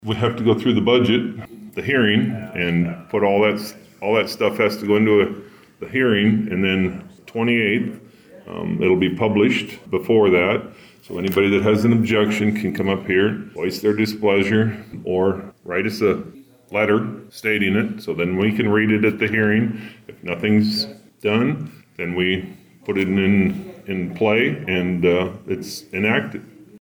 Board of Supervisors Chairman Bruce Reimers explains what’s next in the process.